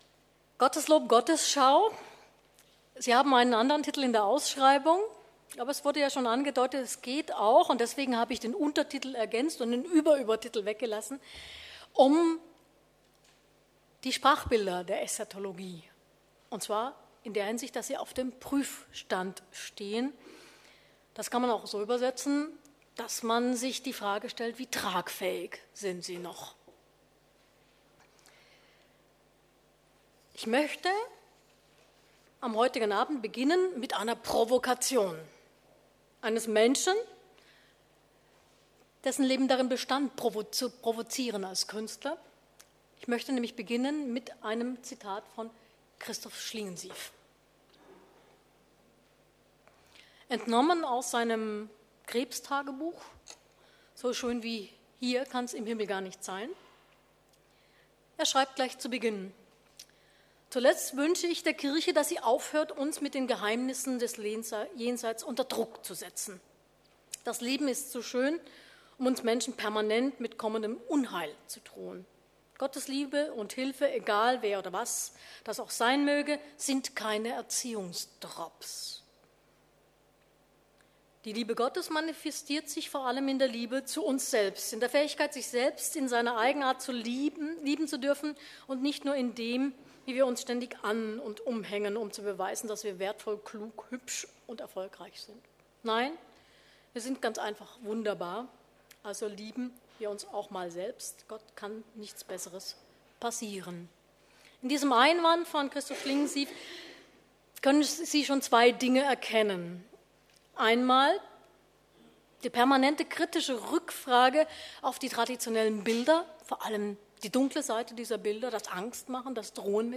Sie können hier den ersten Teil des Vortrags (ohne Publikumsfragen) nach-hören.